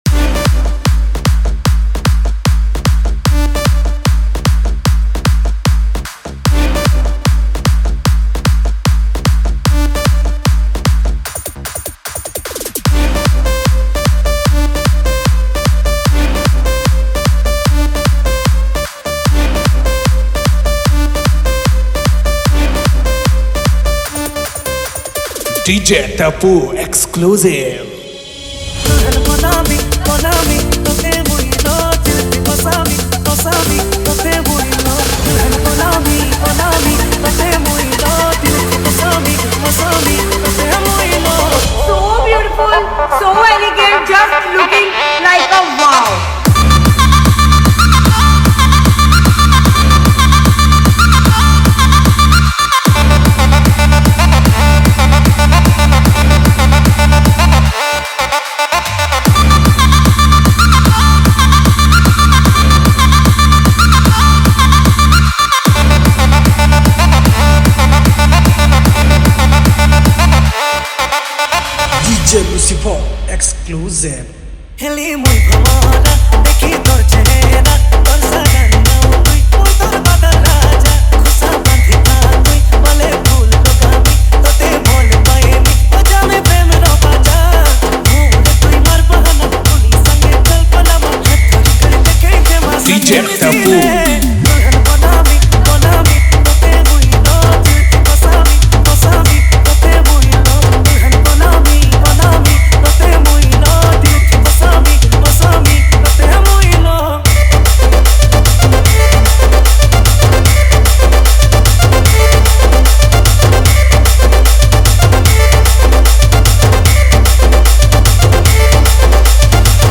Category:  New Sambalpuri Dj Song 2023